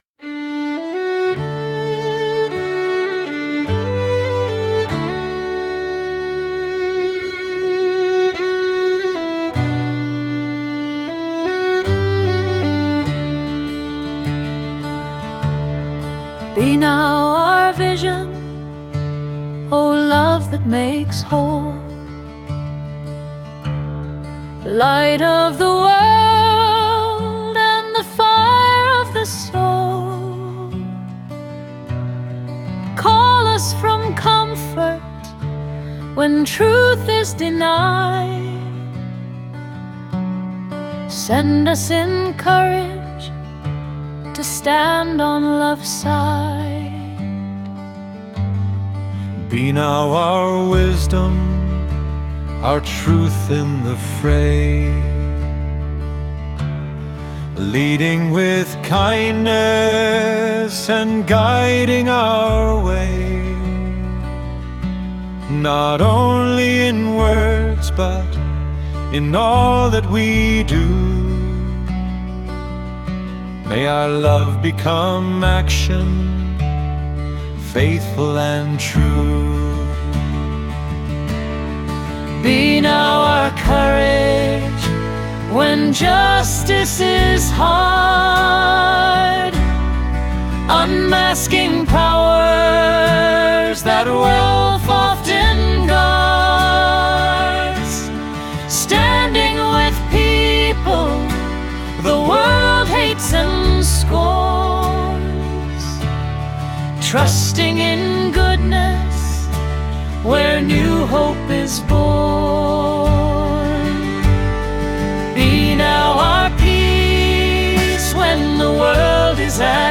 It is based on a traditional Irish tune called “Slane.”
This is a recent recording which is fairly true to the melody of the hymn. (I think I have finally got the hang of the Suno AI software that I used to produce it.)
Audio, Compassion, Discipleship, Jesus, Justice, Love, Nonviolence, Peace, Politics, Reign of God, Song/Hymn